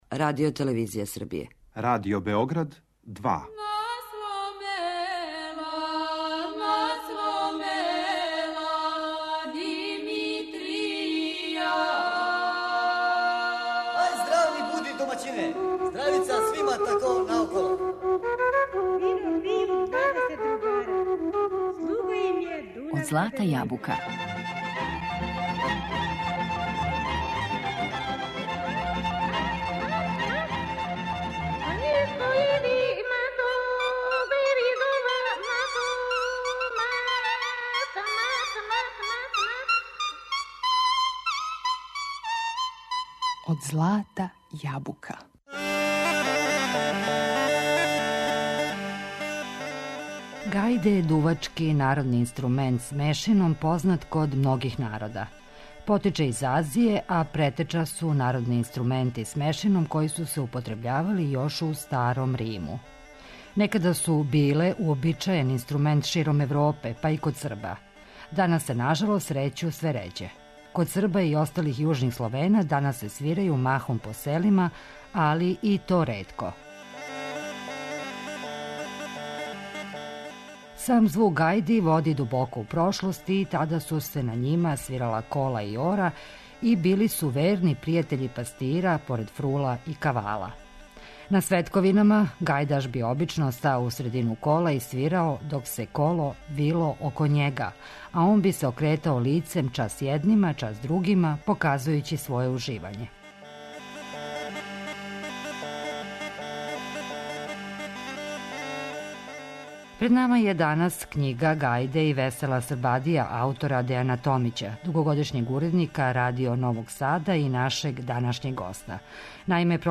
У емисији слушамо снимке познатих гајдаша